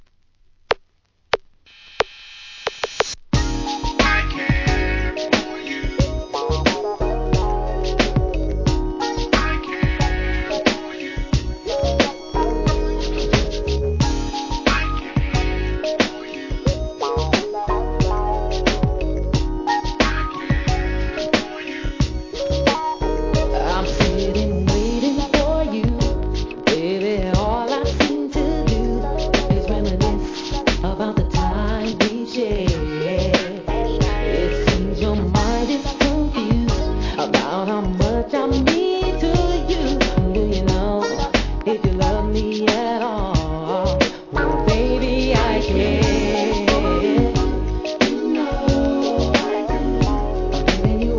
HIP HOP/R&B
TALKBOXをフューチャーした